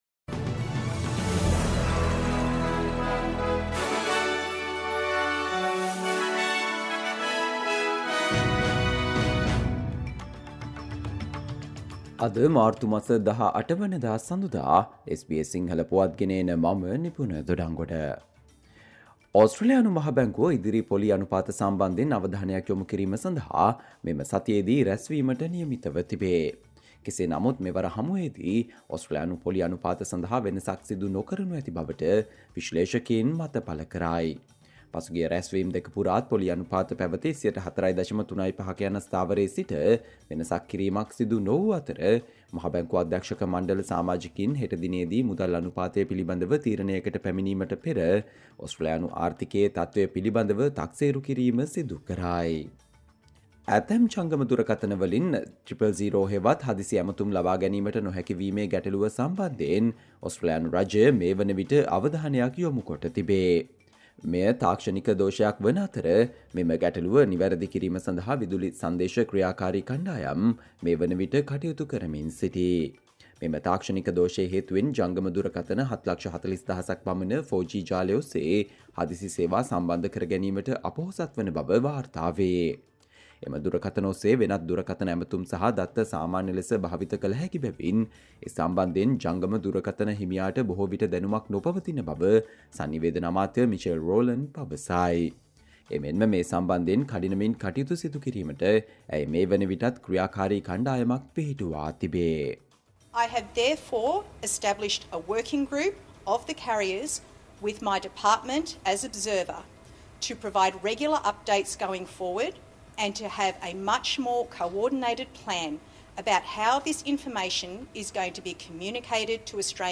Australia news in Sinhala, foreign and sports news in brief - listen, Monday 18 March 2024 SBS Sinhala Radio News Flash